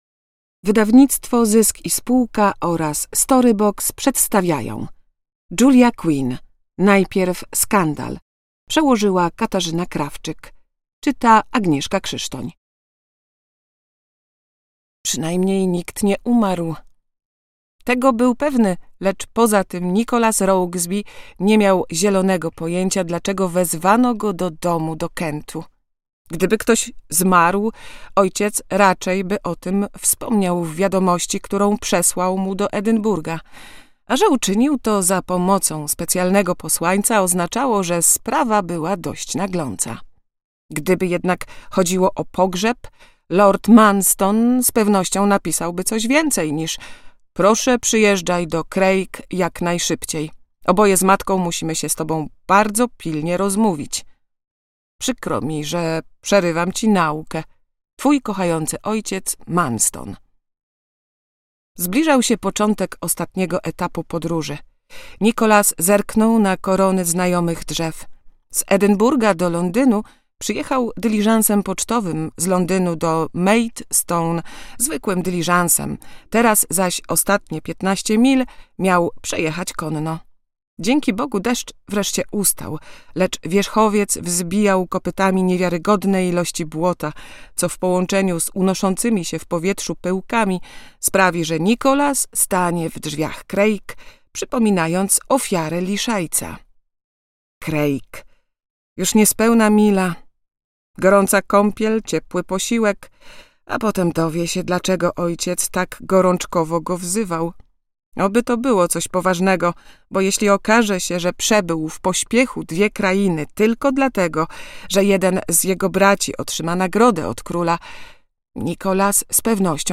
Najpierw skandal /Bridgertonowie/ prequel - Julia Quinn - audiobook